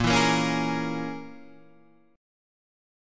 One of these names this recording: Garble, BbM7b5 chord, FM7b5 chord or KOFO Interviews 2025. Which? BbM7b5 chord